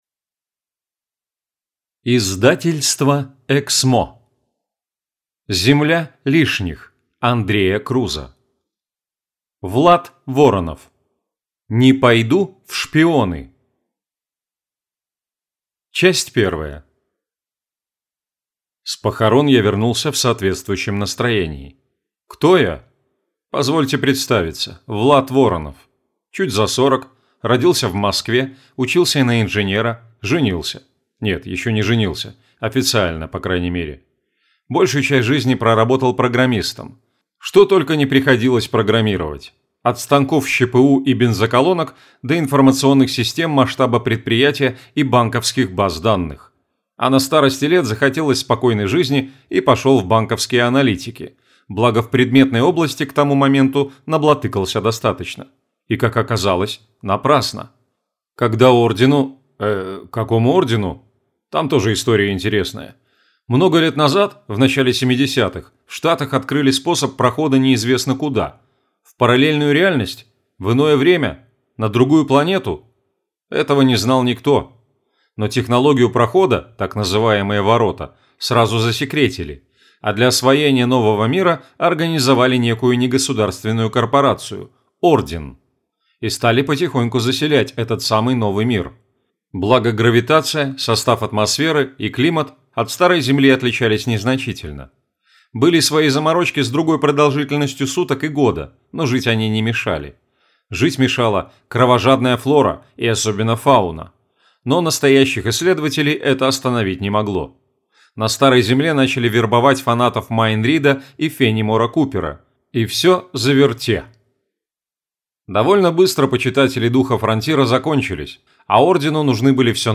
Аудиокнига Земля лишних. Не пойду в шпионы | Библиотека аудиокниг